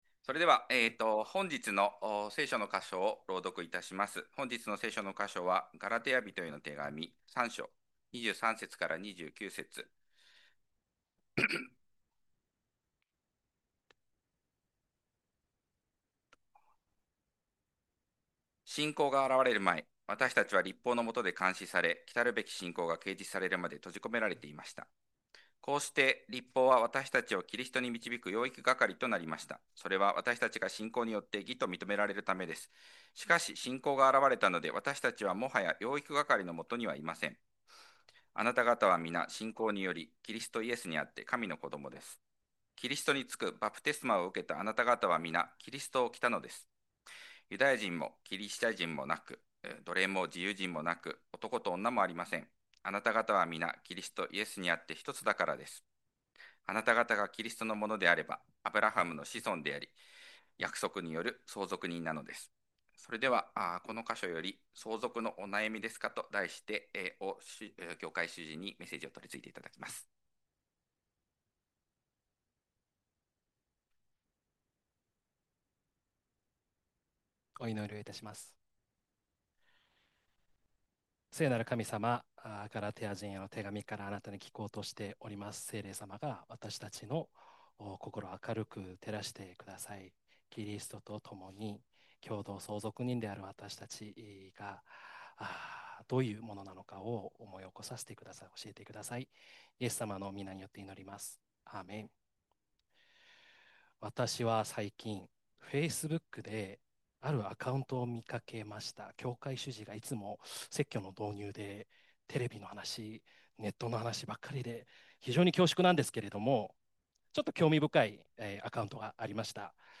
2026年4月19日礼拝 説教 「相続のお悩みですか？」 – 海浜幕張めぐみ教会 – Kaihin Makuhari Grace Church